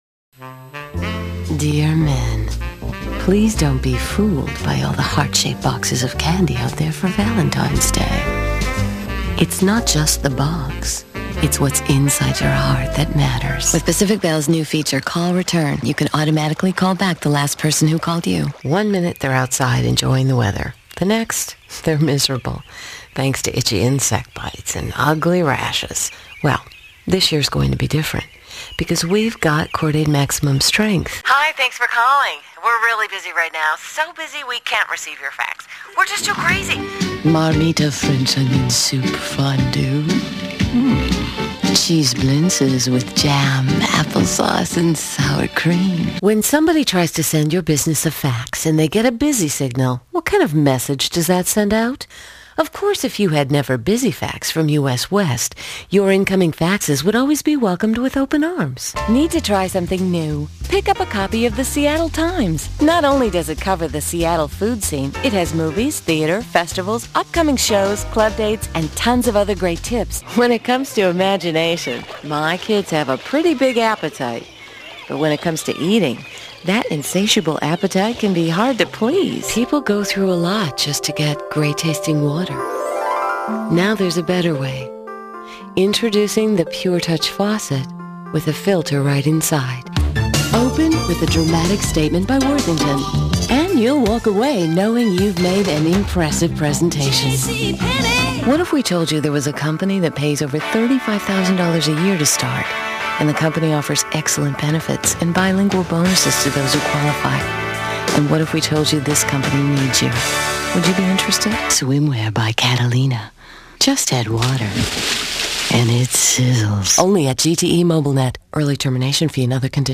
Voiceover
Commercials (2:00)